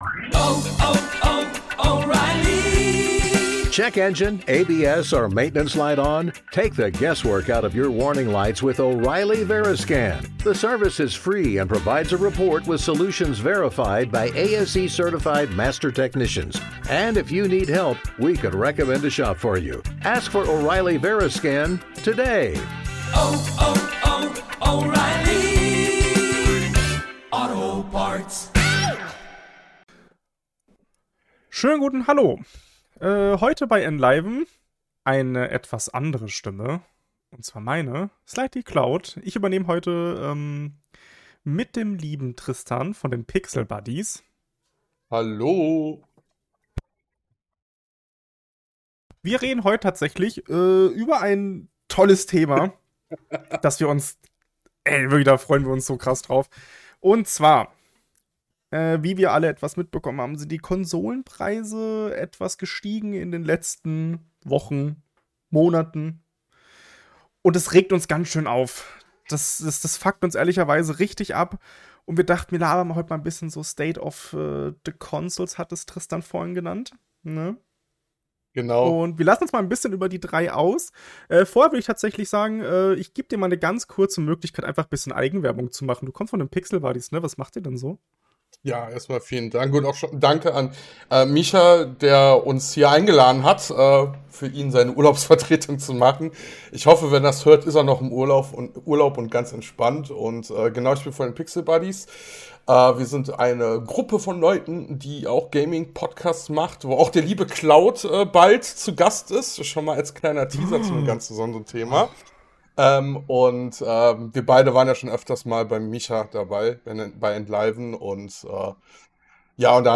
Und wenn sie schon Narrenfreiheit auf EnLiven haben, tun sie das, worin sie am besten sind: wie zwei alte Herren über die aktuelle Gamingbranche sprechen. Was geht gerade bei Microsoft, Sony und Nintendo ab?